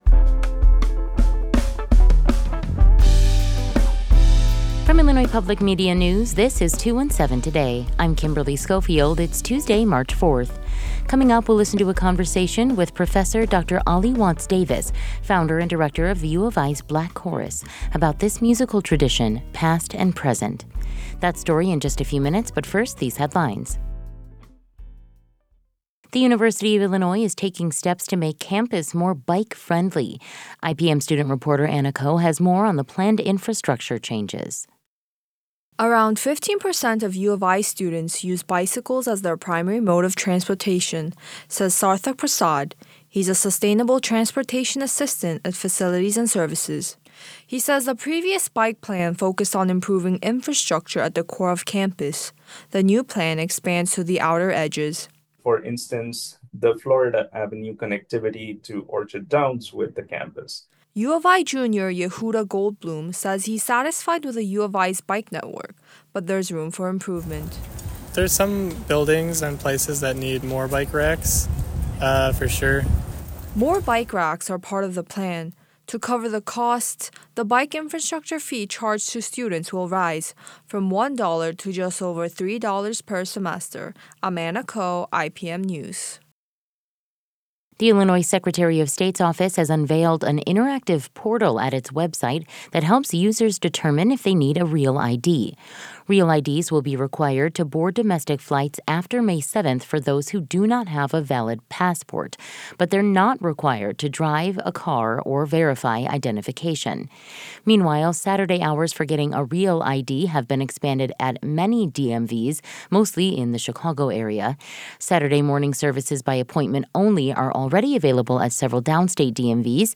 Today's headlines: The University of Illinois is taking steps to make campus more bike-friendly.